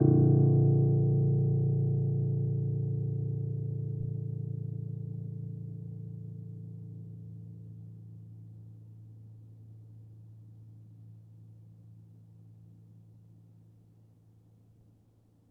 Upright Piano